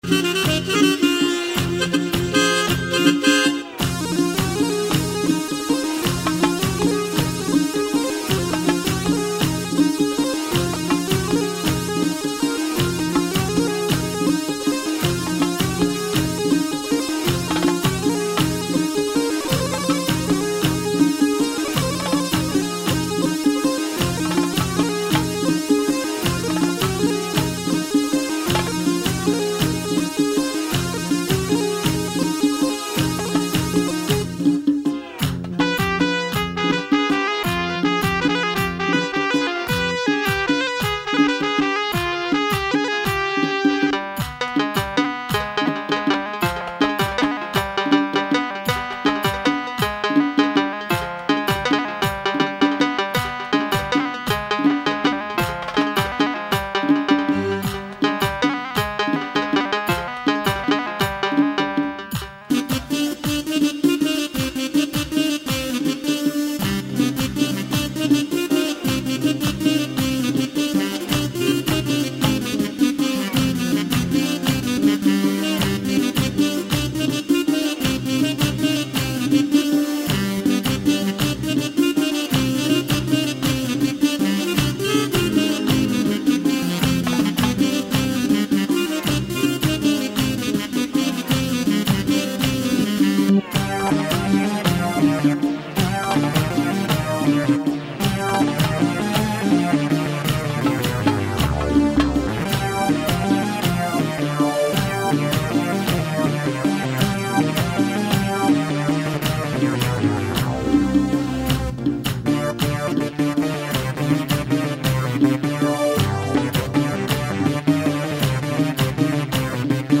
Lori song